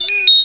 Star Wars R2D2 5 Sound Effect Free Download